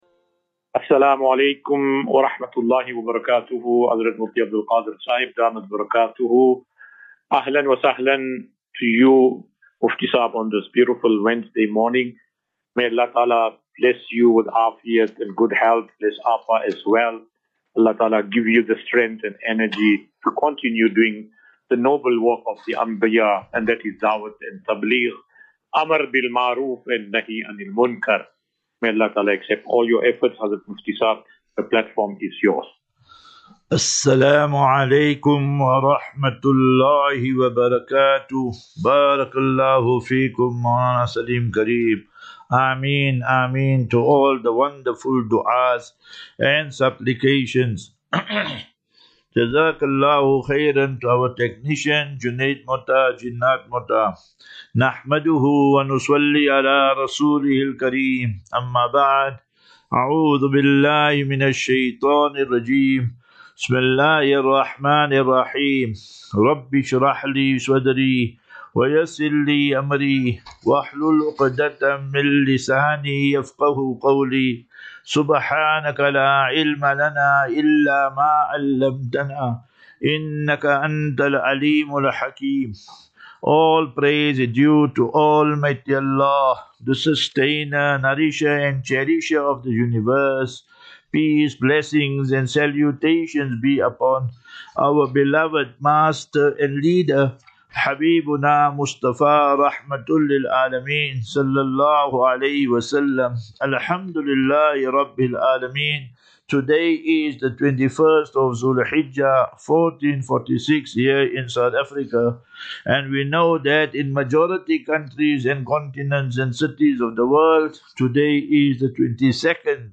QnA.